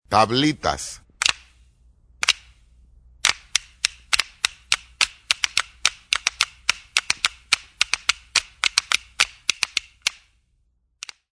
Sonidos de instrumentos Afroperuanos
"Tablitas"
tablitas.mp3